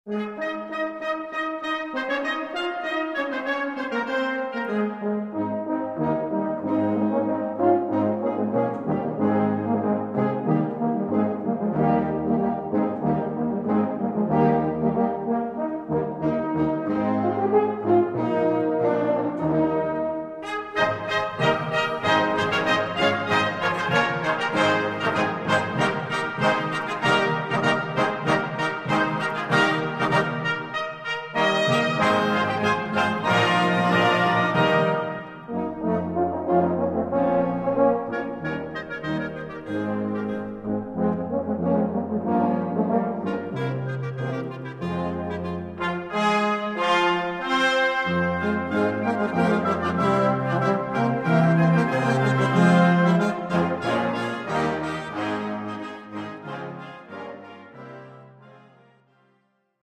Gattung: Feierliche Musik
Besetzung: Blasorchester